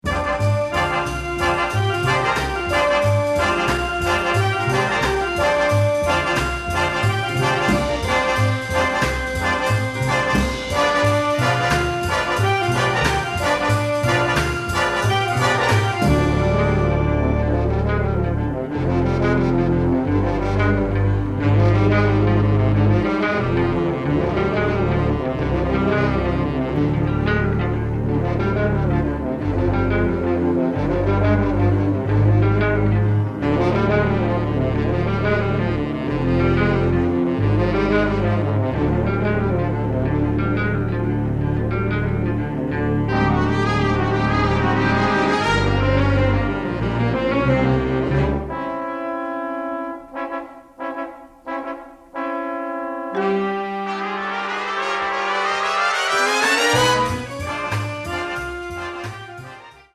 shifting woodwind lines, big band-flavored fight music